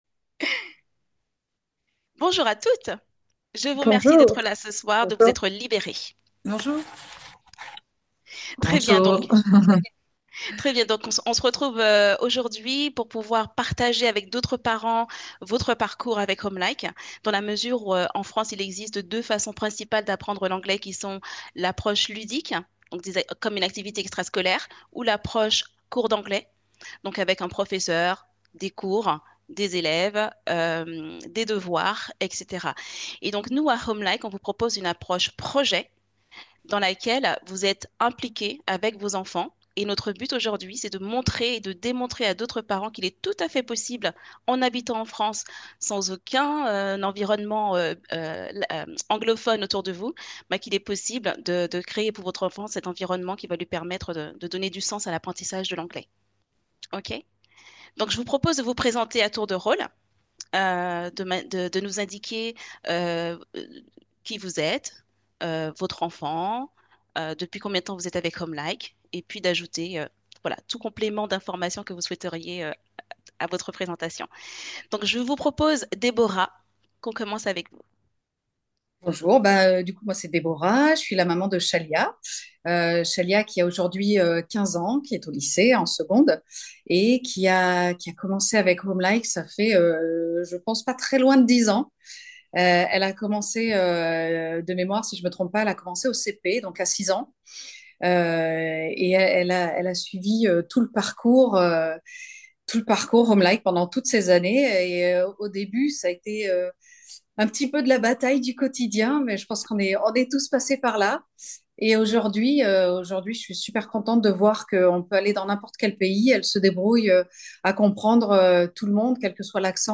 Elles sont 8 mamans. 7 d’entre elles ne parlent pas anglais.
temoignage-parent.mp3